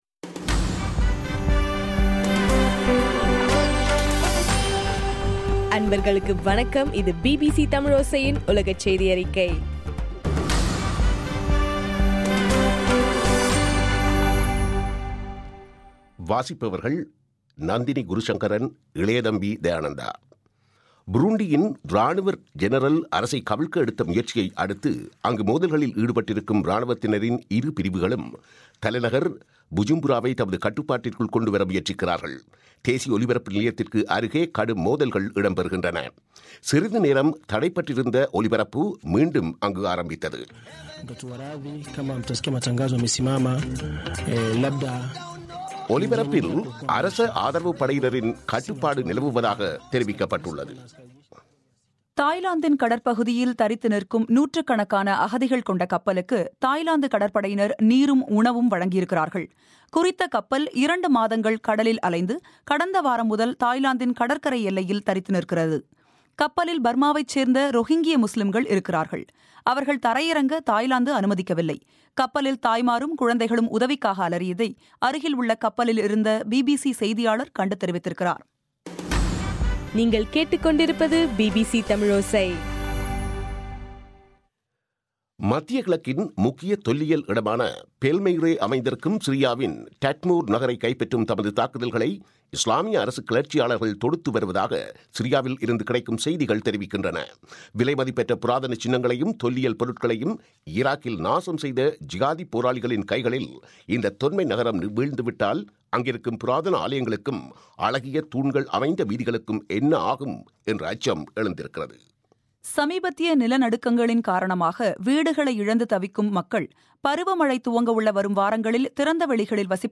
மே 14, பிபிசியின் உலகச் செய்திகள்